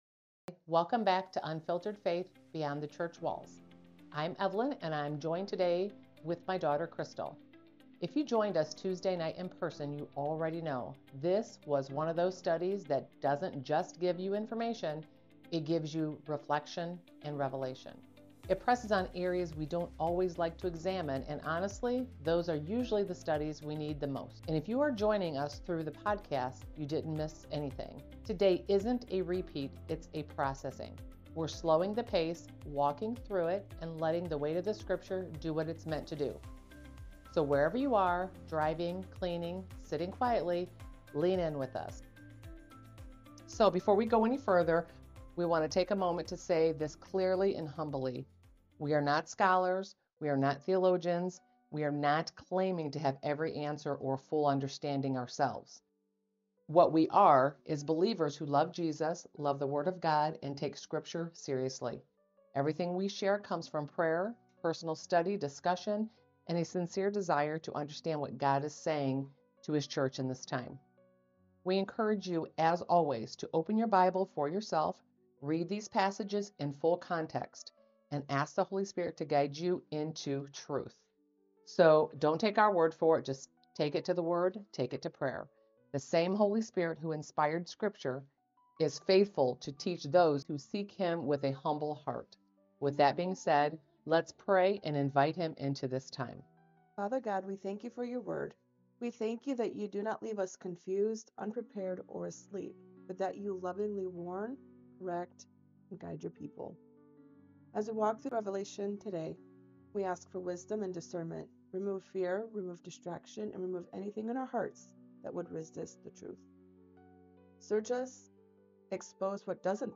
This is Bible-based, honest conversation about comfort, compromise, and what it means to live aligned with God’s Kingdom in a culture pulling us away from truth.